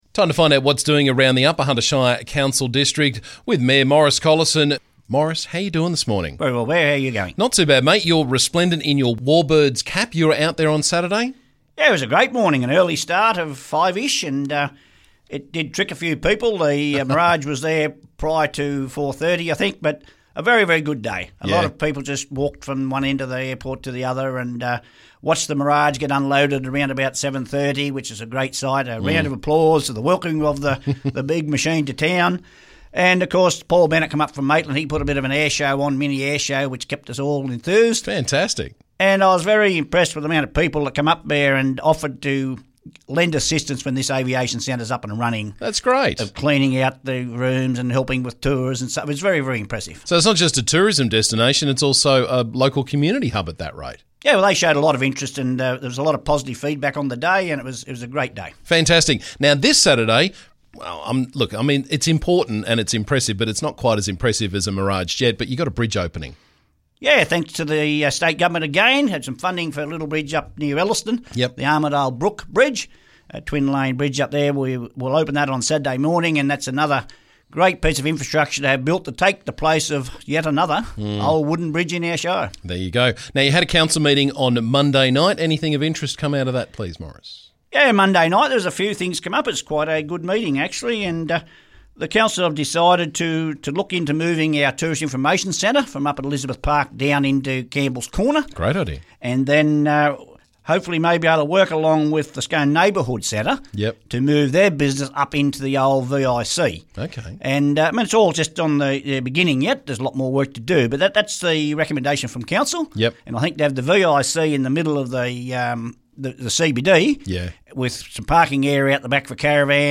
Upper Hunter Shire Council Mayor Maurice Collison caught us up with the latest from around the district.